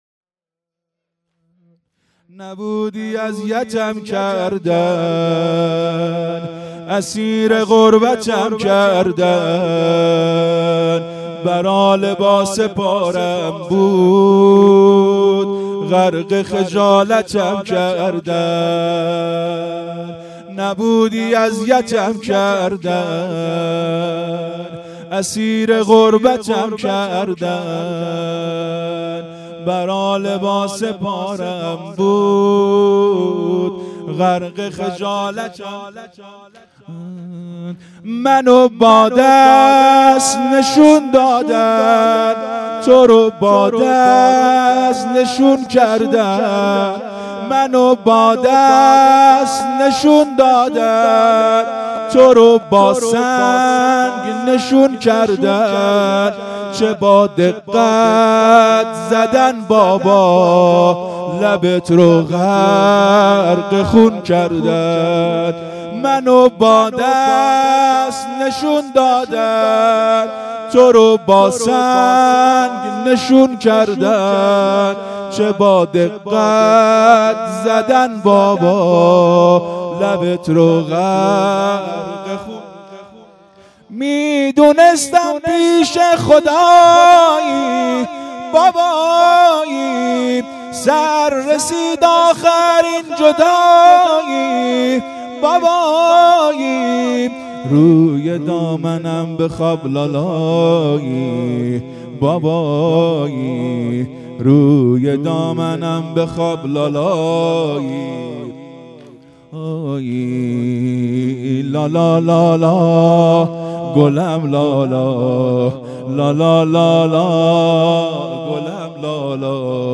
زمینه | نبودی اذیتم کردن مداح
مراسم عزاداری محرم الحرام ۱۴۴۳_شب سوم